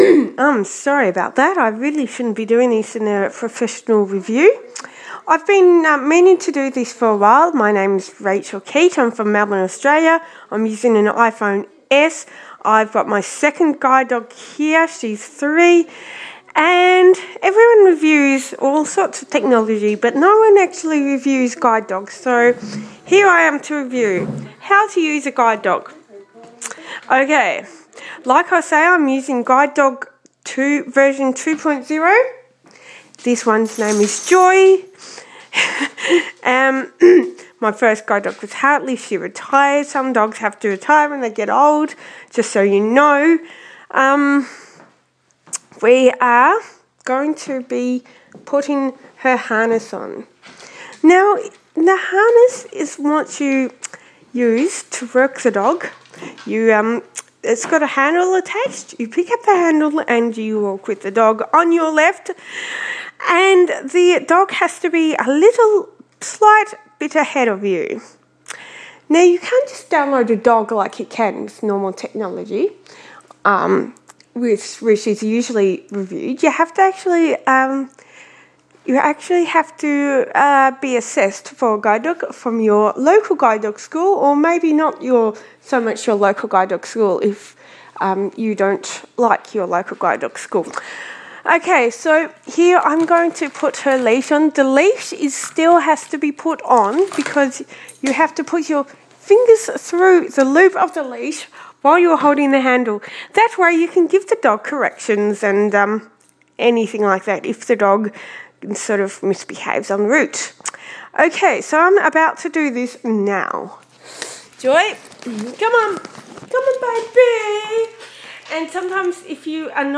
This is a mock interview of the guide dog :)